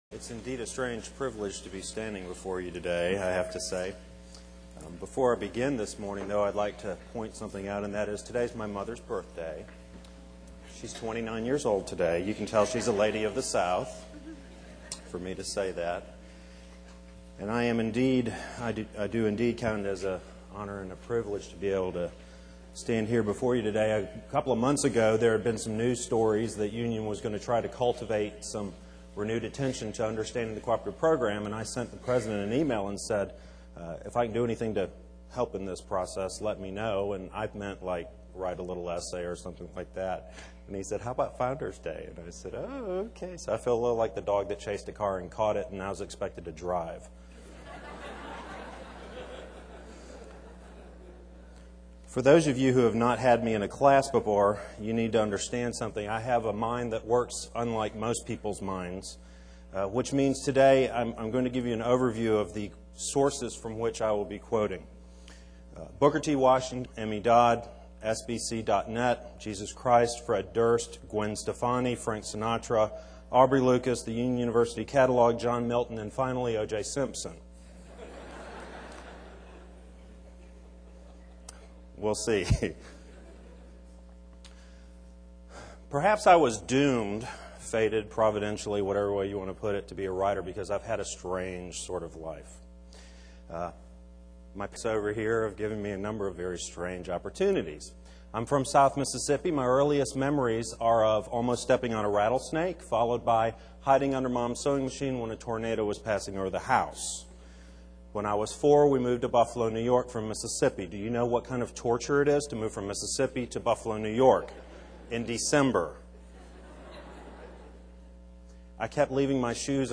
Chapel Service: Founders Day